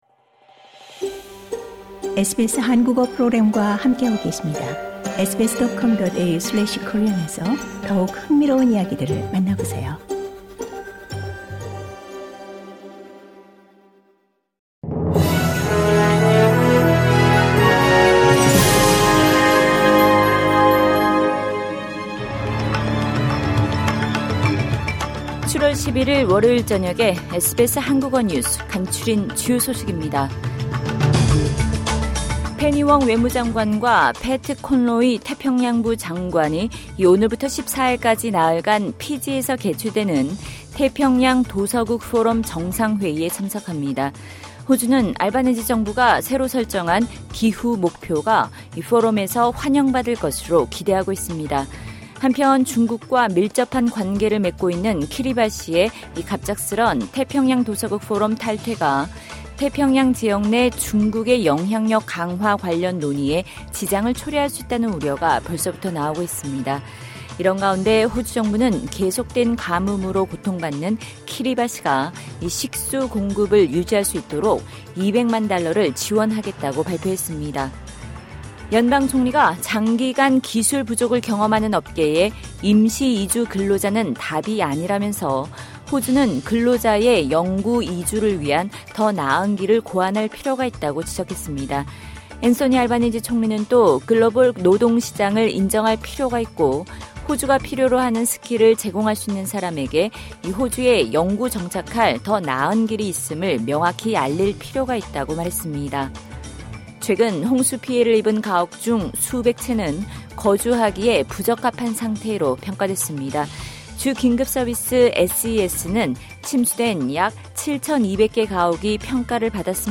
2022년 7월 11일 월요일 저녁 SBS 한국어 간추린 주요 뉴스입니다.